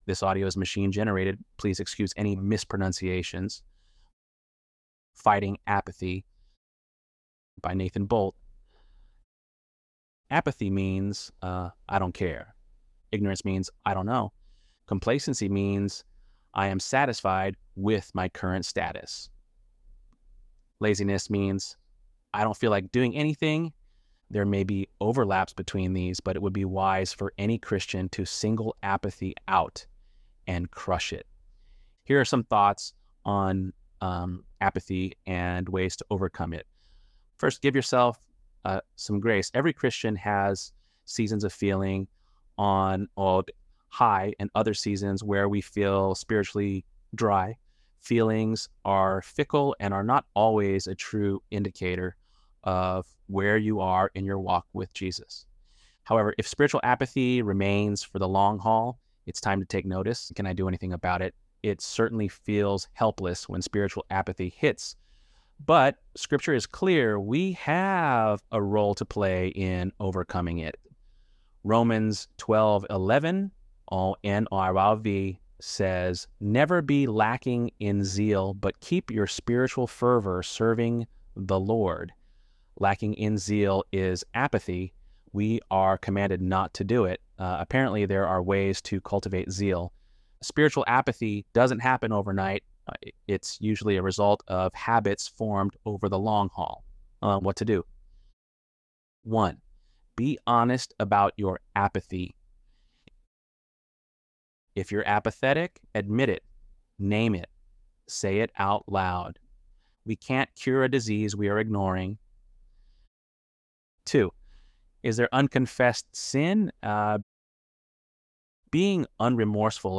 ElevenLabs_1_.mp3